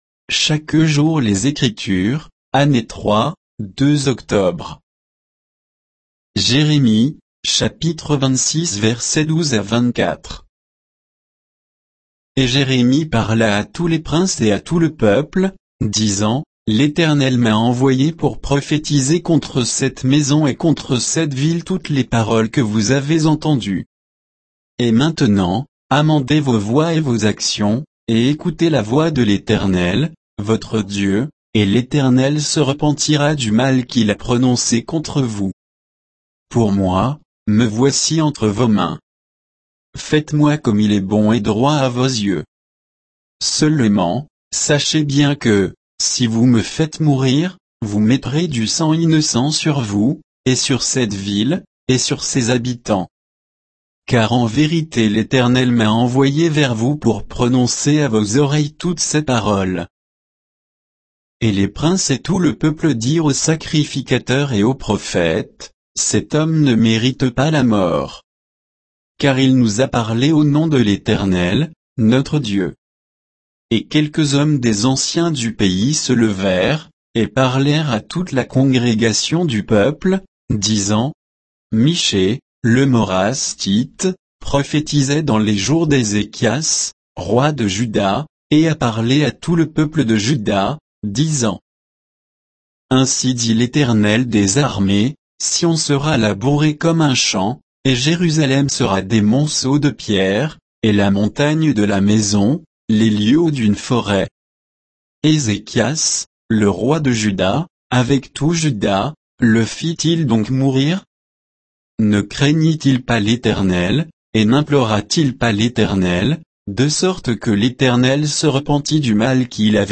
Méditation quoditienne de Chaque jour les Écritures sur Jérémie 26